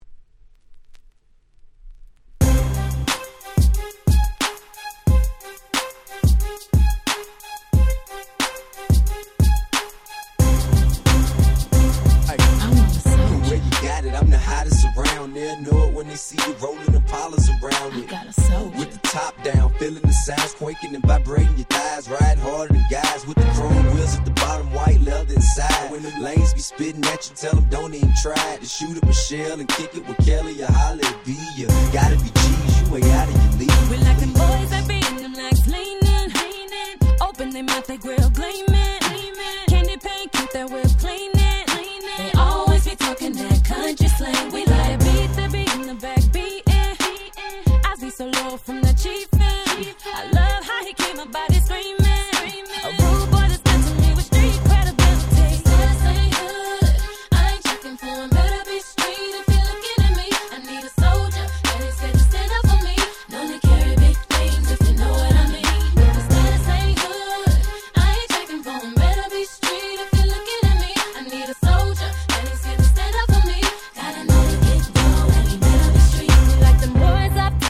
04' Super Hit R&B !!